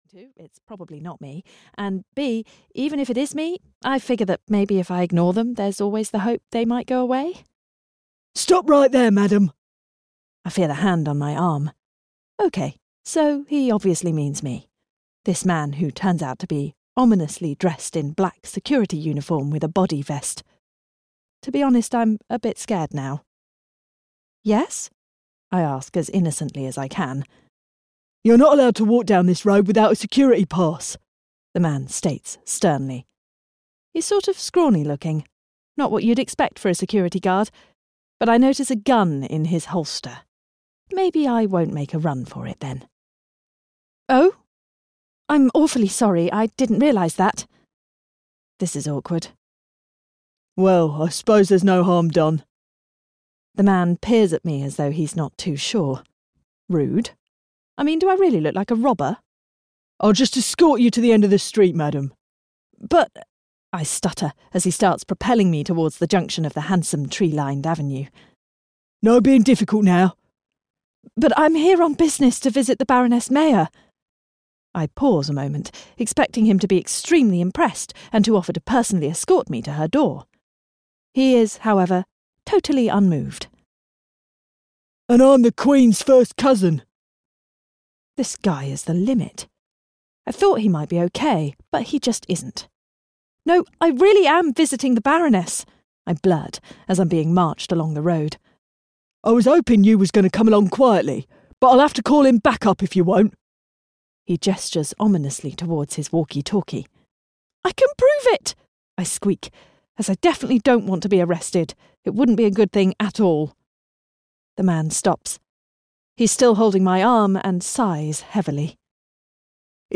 A Wedding at the Jane Austen Dating Agency (EN) audiokniha
Ukázka z knihy